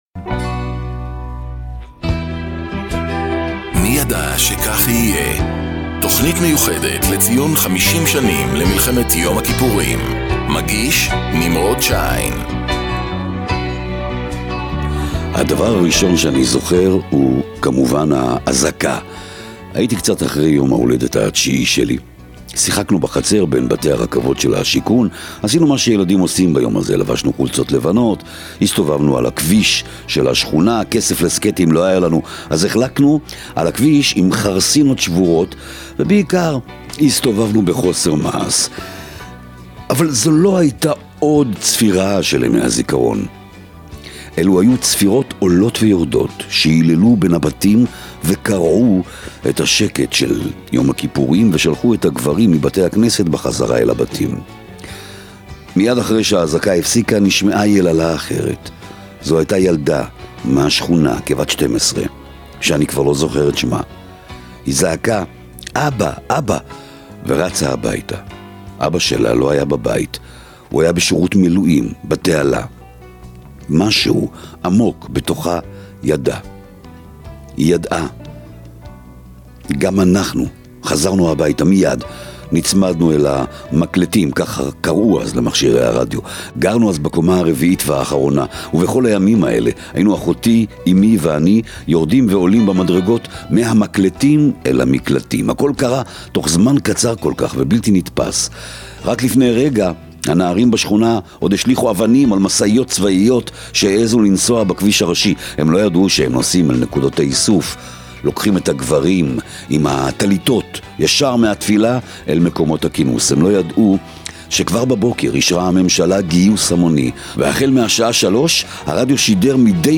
תכנית מיוחדת לציון 50 שנים למלחמת יום הכיפורים